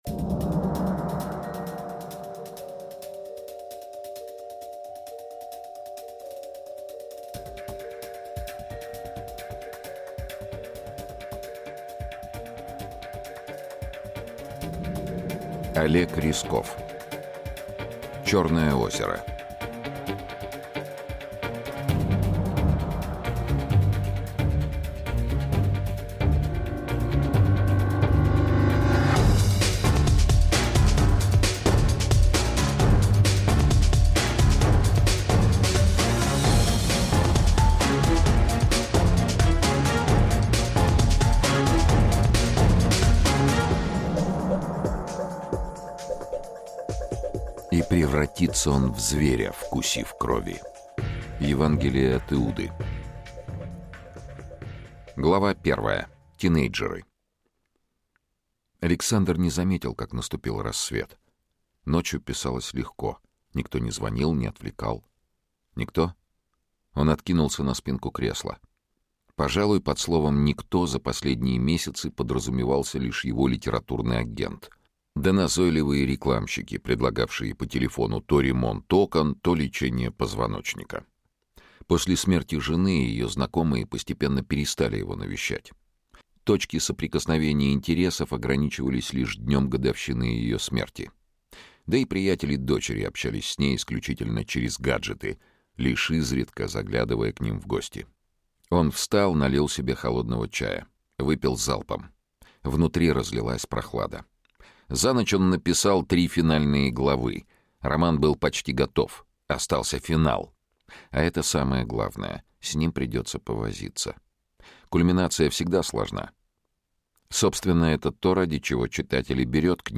Аудиокнига Черное озеро | Библиотека аудиокниг
Aудиокнига Черное озеро Автор Олег Рясков Читает аудиокнигу Сергей Чонишвили.